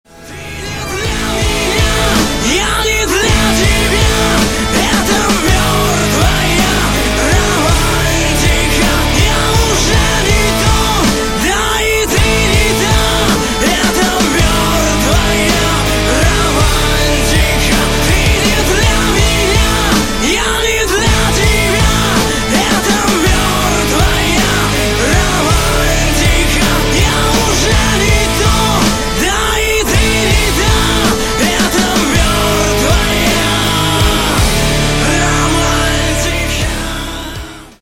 Рингтоны Альтернатива
Рок Металл Рингтоны